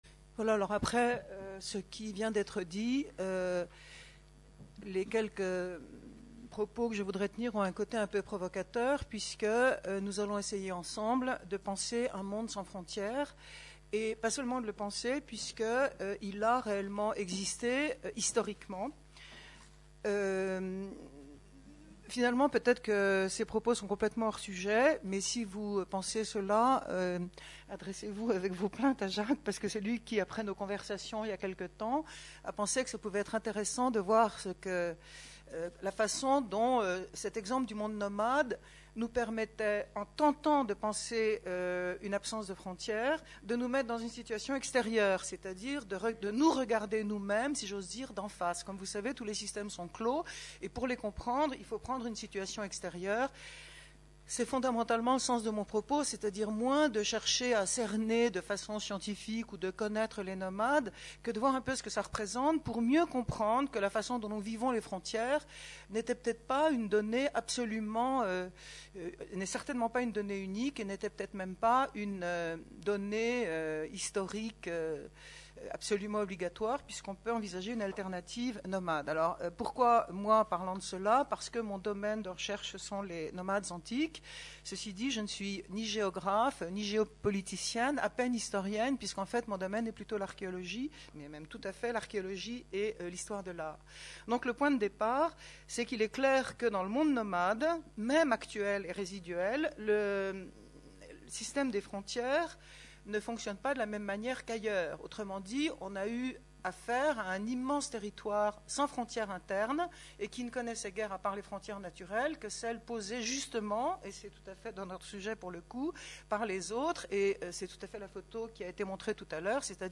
Conférence des RED (Festival Est-Ouest 2008)